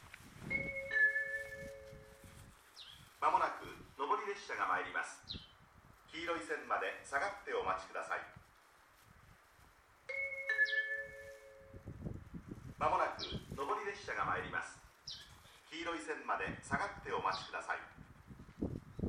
２番線奥羽本線
接近放送普通　湯沢行き接近放送です。